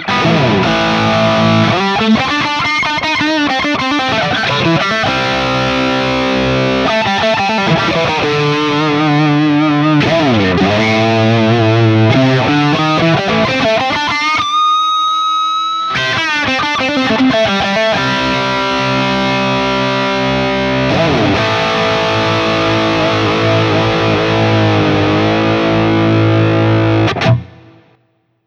EVH
Pentatonic Wankery
As usual, for these recordings I used my normal Axe-FX II XL+ setup through the QSC K12 speaker recorded direct into my Macbook Pro using Audacity.
That one’s just pure bridge pickup tone.
Guild-X97V-EVH-PentatonicWankery.wav